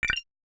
Simple Cute Alert 26.wav